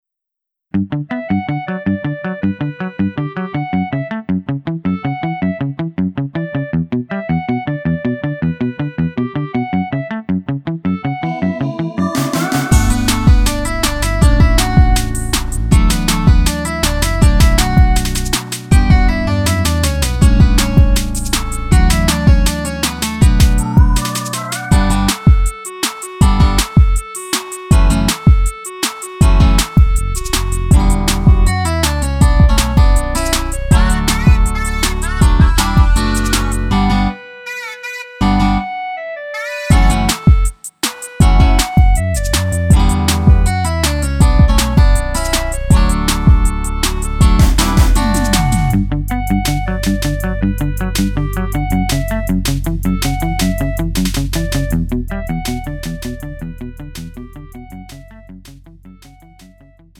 음정 -1키 2:53
장르 가요 구분